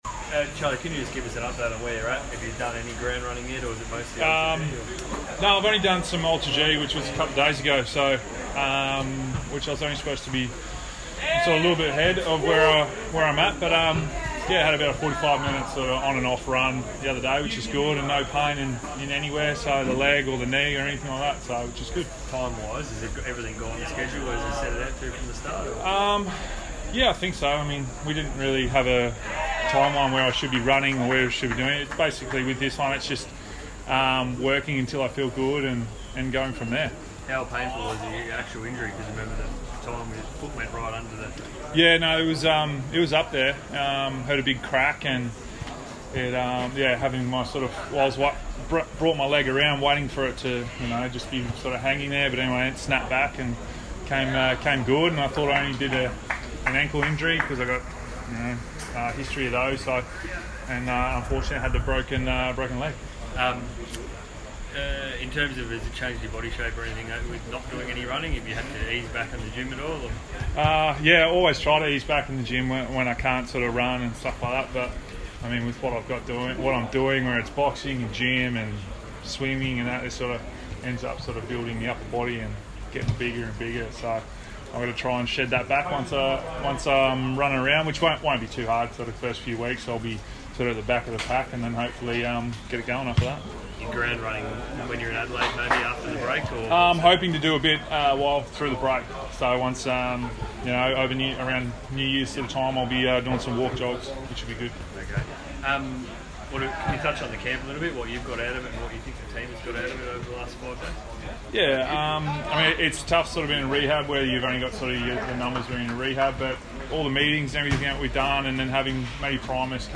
Charlie Dixon press conference - Sunday 16 December